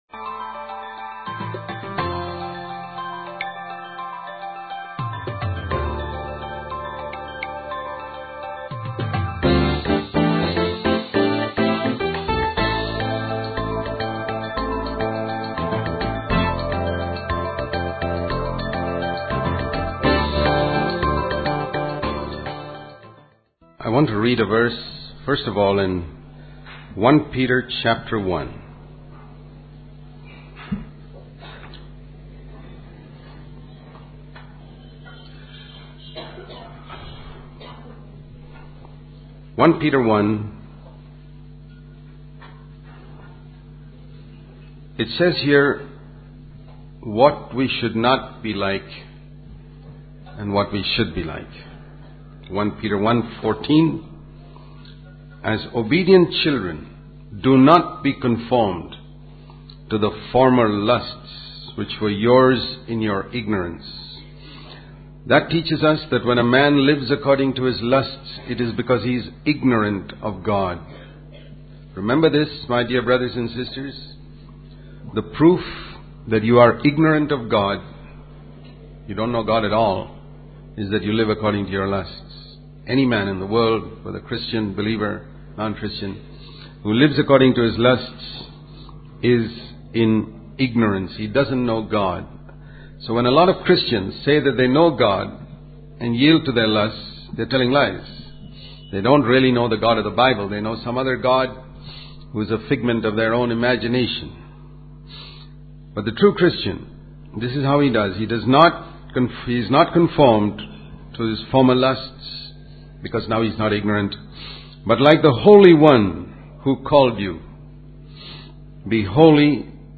In this sermon, the preacher discusses the influence of popular culture, such as books like Harry Potter, on people's acceptance of witchcraft. He emphasizes the importance of understanding God's unconditional love and acceptance, and the concept of justification, which means being free from sin. The preacher also highlights the need for Christians to show gratitude for Jesus' sacrifice by offering acceptable service to God with reverence and awe.